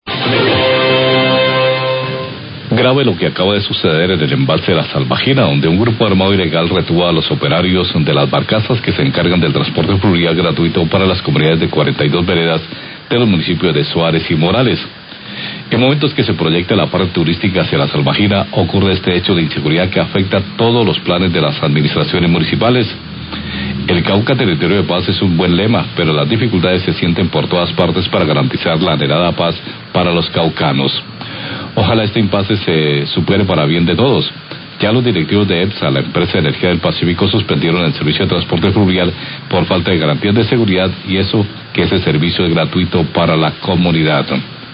Radio
editorial